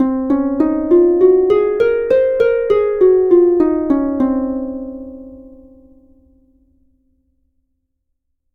Escala locria
arpa
sintetizador